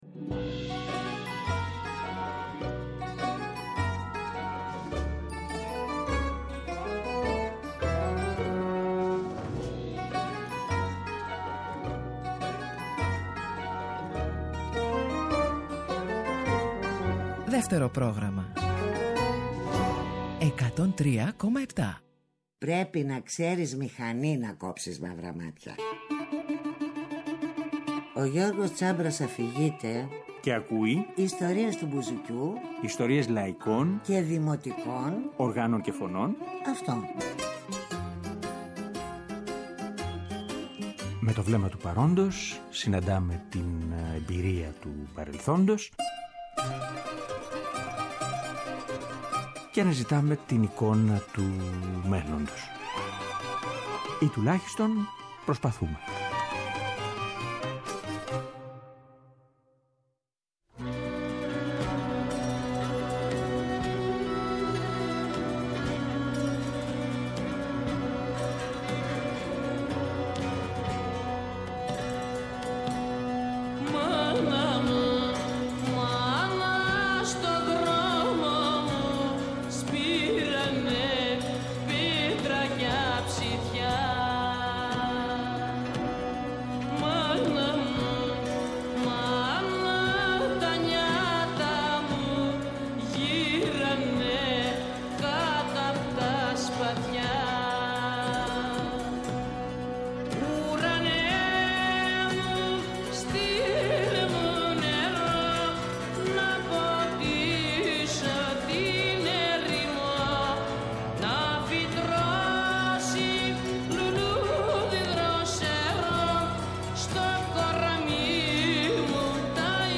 Και στην τελευταία ενότητα, μια ανέκδοτη ηχογράφηση του Χριστόδουλου Χάλαρη με την Ελένη Βιτάλη.